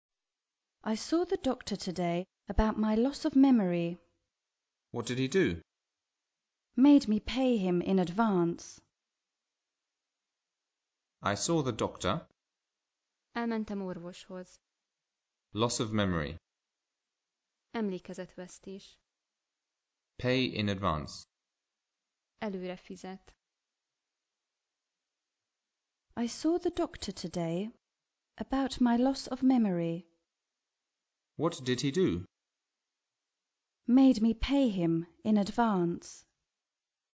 ISBN: Cím: Viccek, adomák CD melléklet Sorozat: Olvassa eredetiben Nyelv: Angol Kiadás: 2006 Ár: 990 Ft Ismertető: Alapfokú nyelvtudással önállóan is feldolgozható hanganyag, mely a Viccek, adomák c. könyvből tartalmaz részleteket anyanyelvűek előadásában.